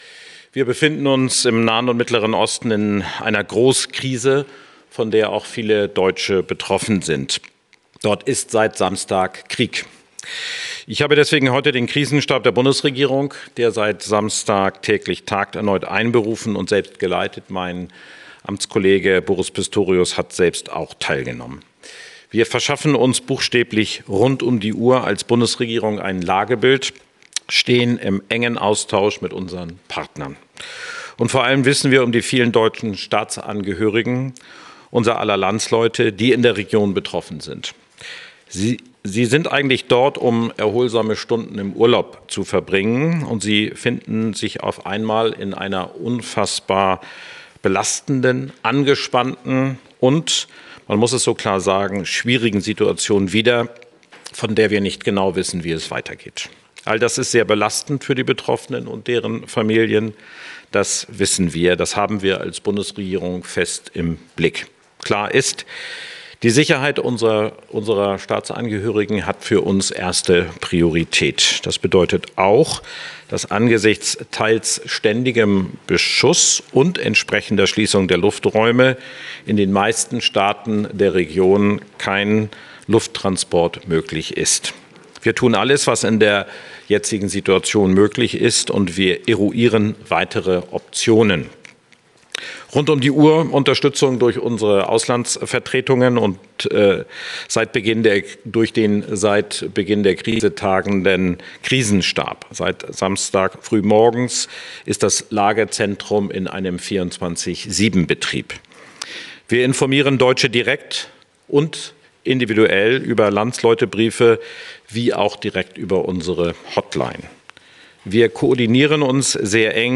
Außenminister Wadephul bei einer Pressekonferenz.